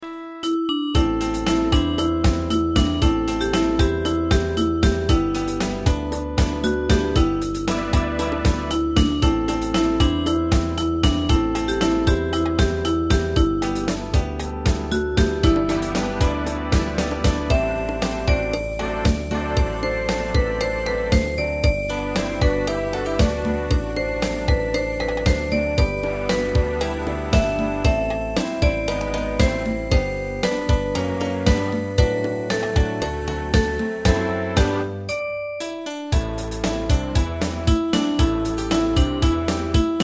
大正琴の「楽譜、練習用の音」データのセットをダウンロードで『すぐに』お届け！